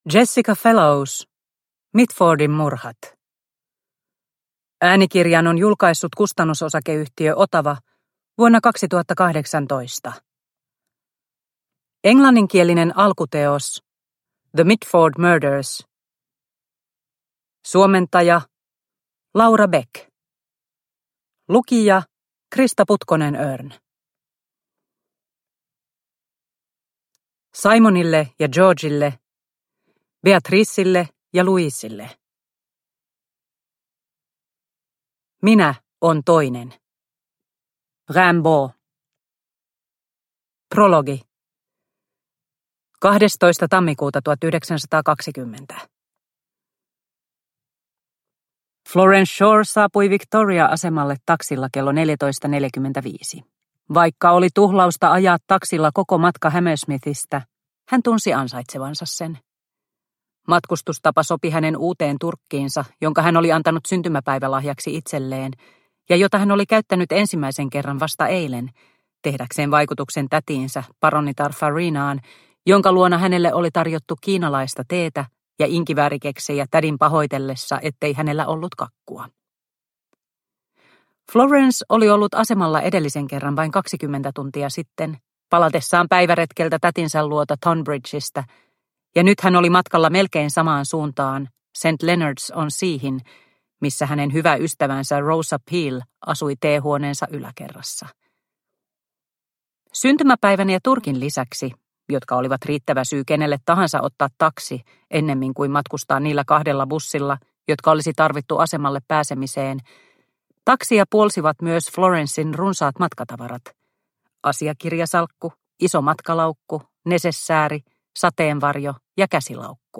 Mitfordin murhat – Ljudbok – Laddas ner